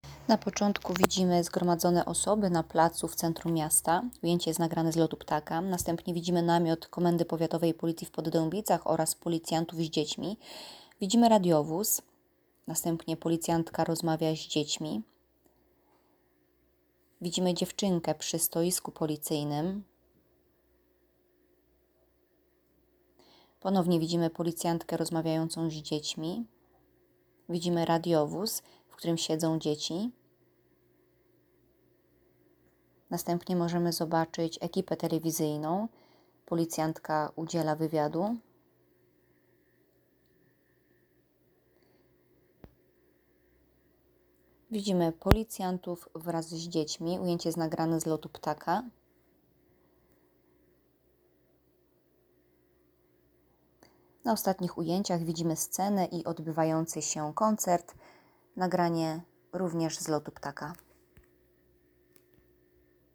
Nagranie audio Audiodeskrypcja.m4a